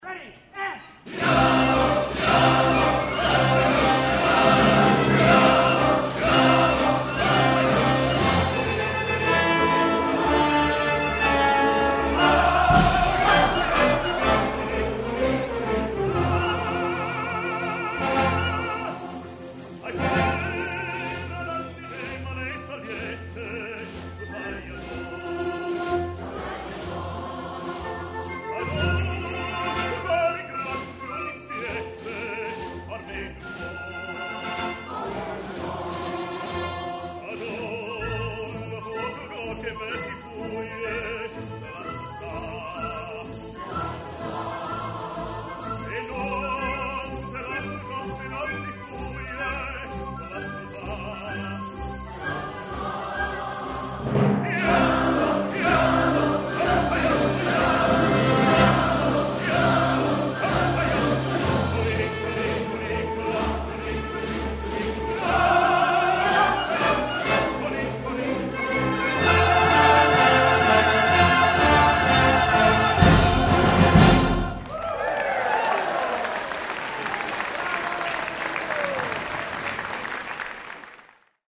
Tenor Soloist